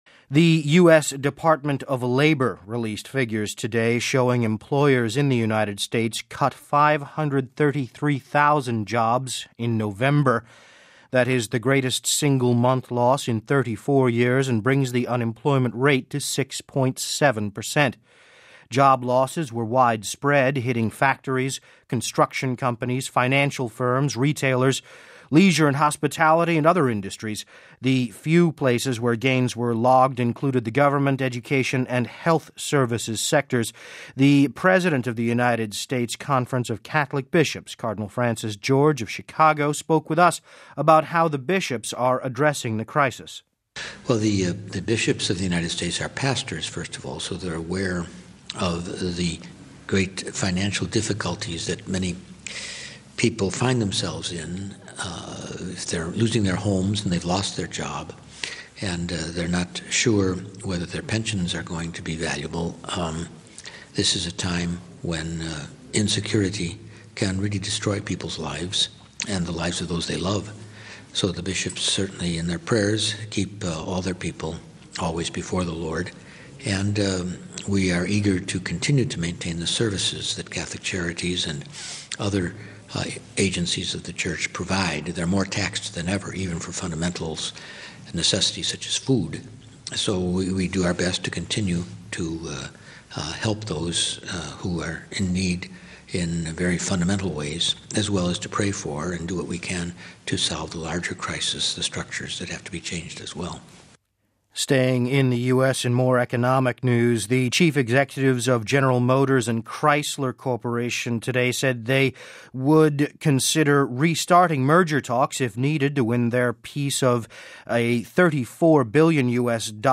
The President of the United States Conference of Catholic Bishops, Cardinal Francis George of Chicago, spoke with us about how the bishops are addressing the economic crisis…